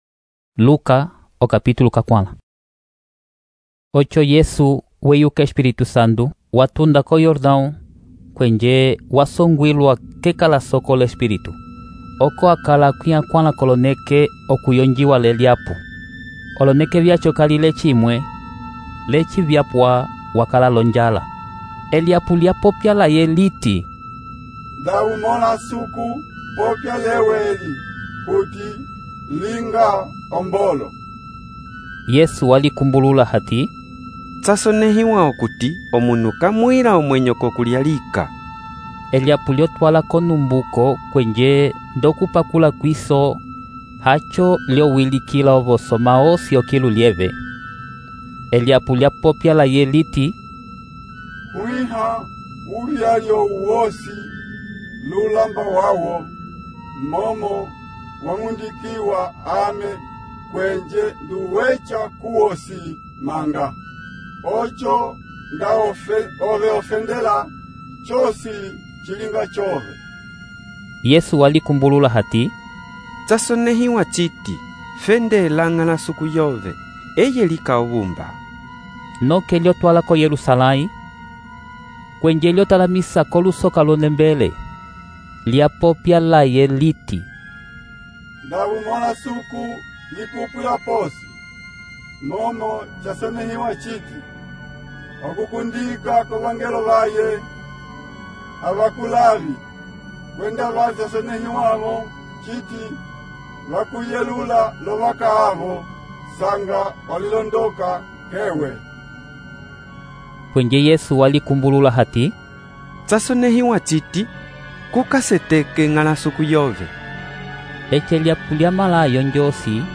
EMBIMBILIYA LIKOLA - Narração em áudio: A tentação de Jesus - Bíblia em Português - Novo Testamento, livro de Lucas, capítulo 4